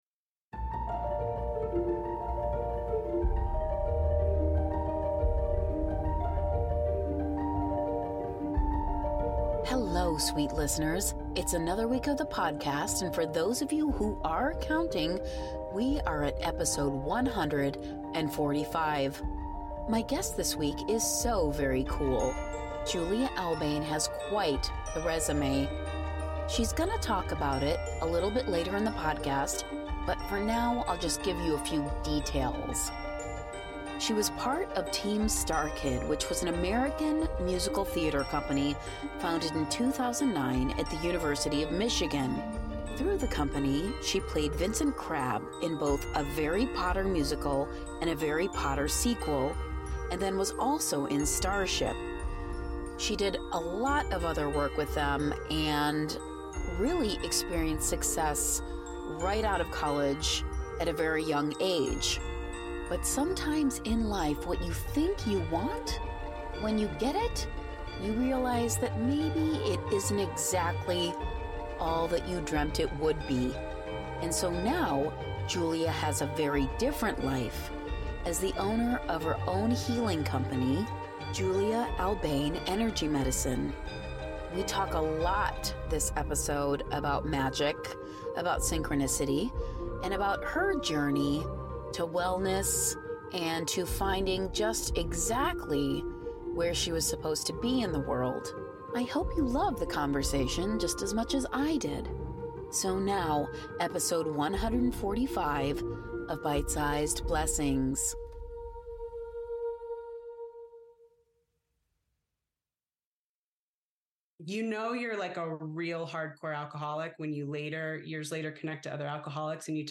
Byte Sized Blessings / The Interview